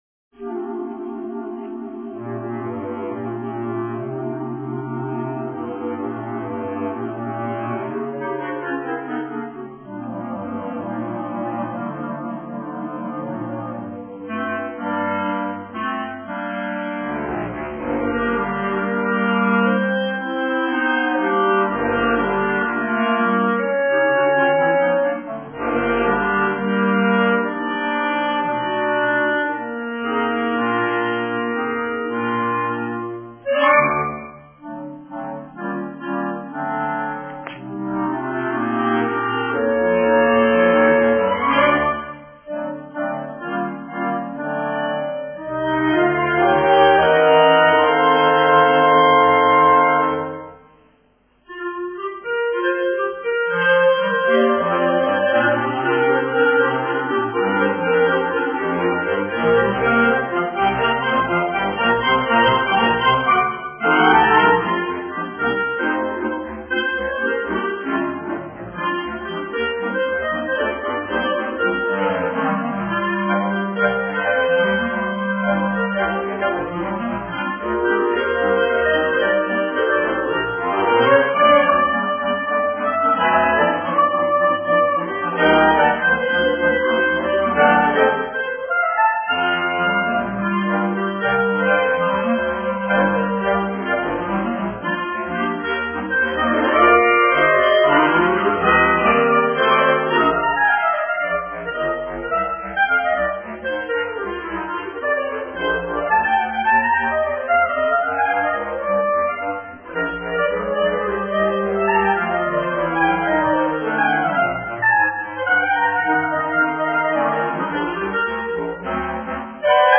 Solo cla
Alto cla
Bass cla